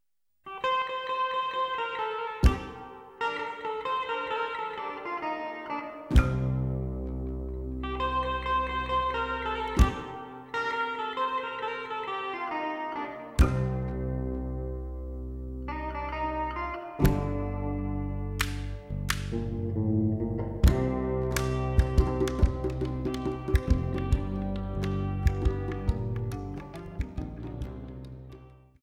the Malian guitarist